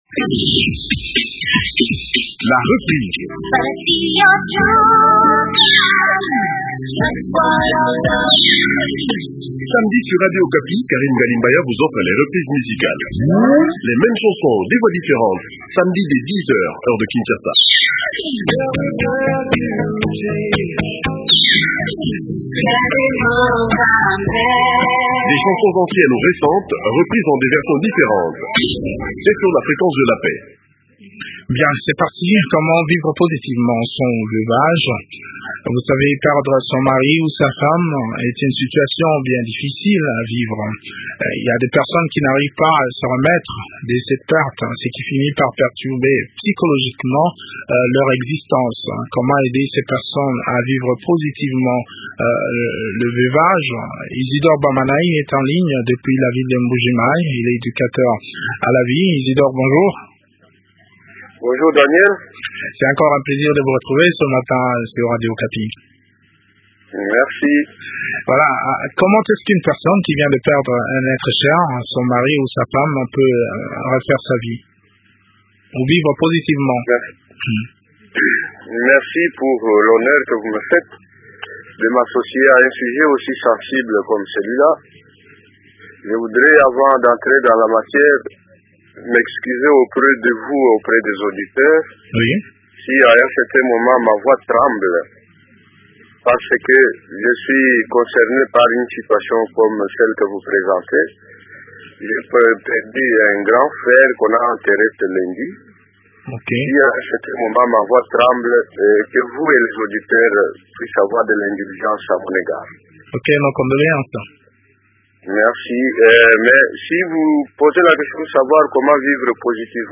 Le point de la situation dans cet entretien